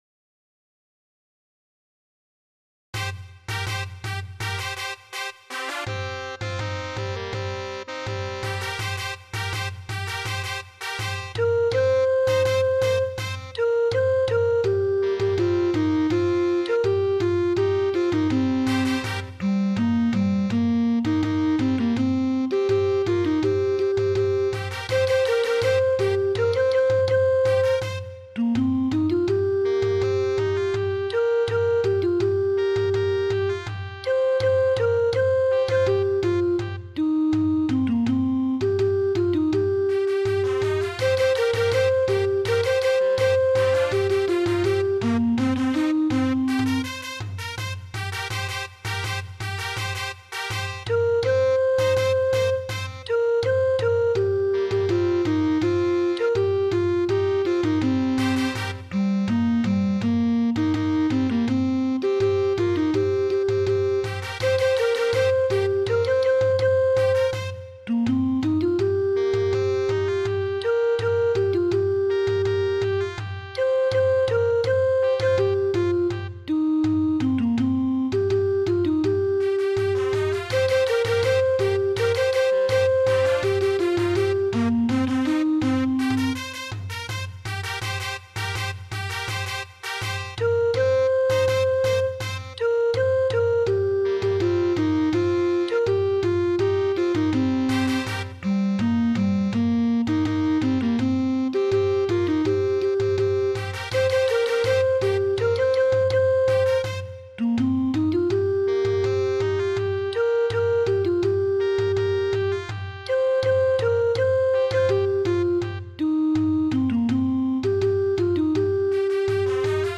（歌なし）